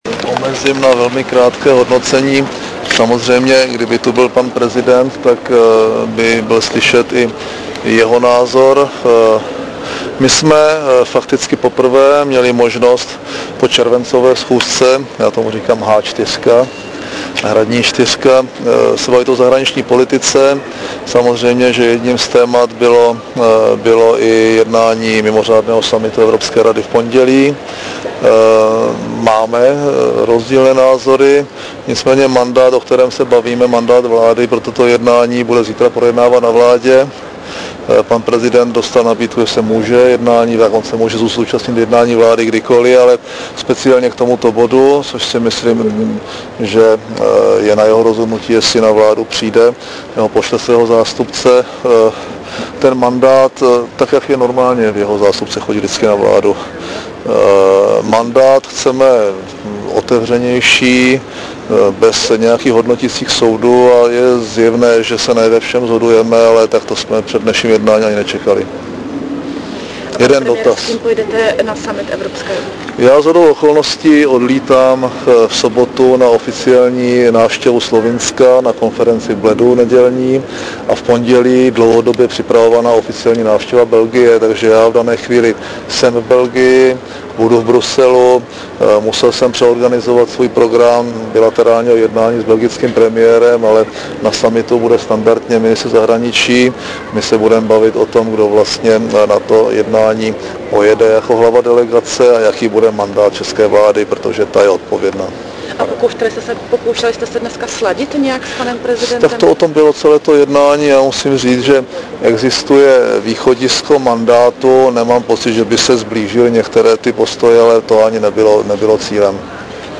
Níže připojená příloha nabízí zvukový záznam komantář předsedy vlády M. Topolánka k dnešní schůzce s prezidentem republiky V. Klausem.